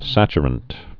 (săchər-ənt)